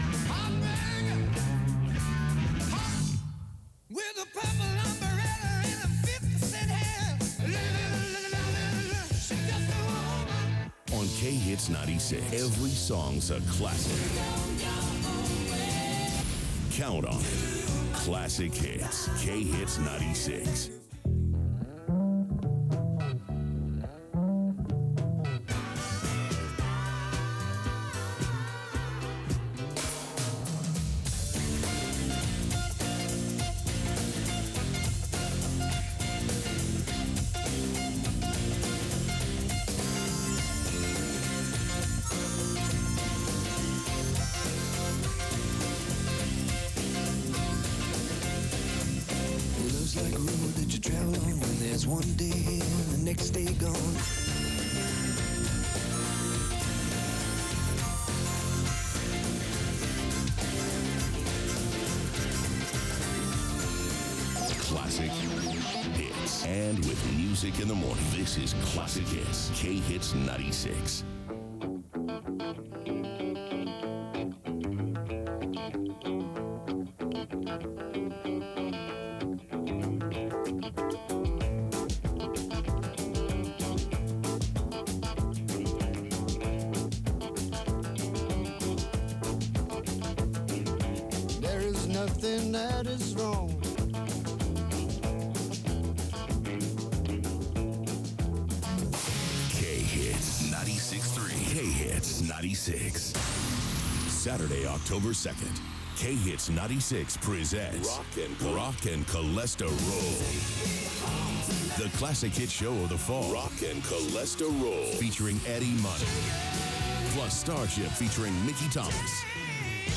KIHT Automated Aircheck · St. Louis Media History Archive